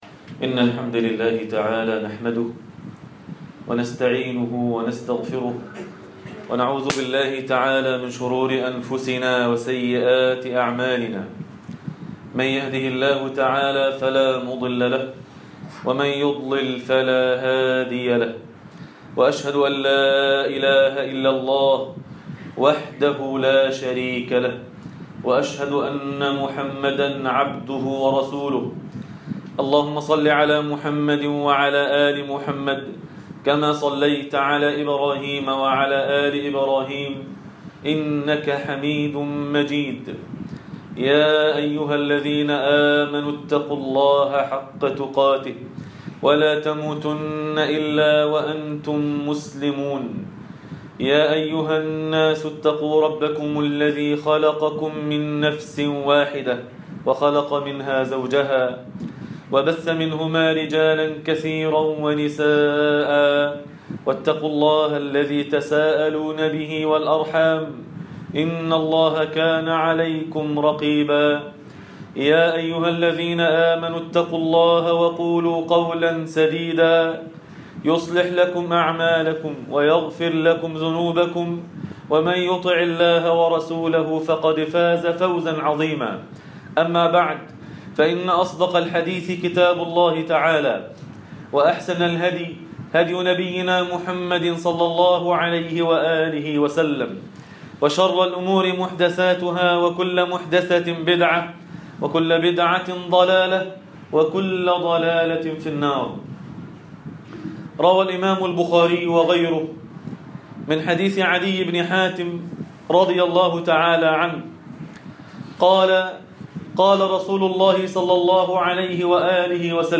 عنوان المادة فوربك لنسألنهم أجمعين | خطبة جمعة تاريخ التحميل الجمعة 10 اكتوبر 2025 مـ حجم المادة 38.22 ميجا بايت عدد الزيارات 66 زيارة عدد مرات الحفظ 43 مرة إستماع المادة حفظ المادة اضف تعليقك أرسل لصديق